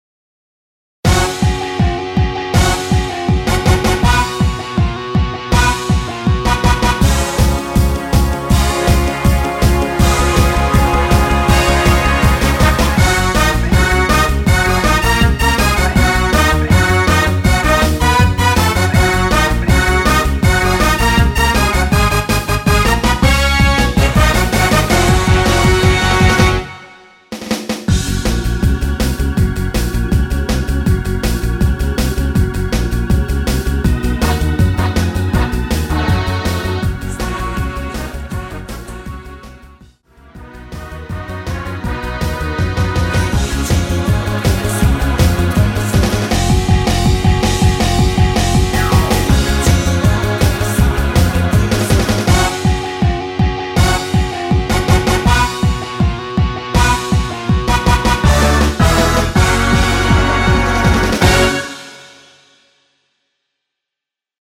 원키 코러스 포함된MR 입니다.(미리듣기 참조)
F#
앞부분30초, 뒷부분30초씩 편집해서 올려 드리고 있습니다.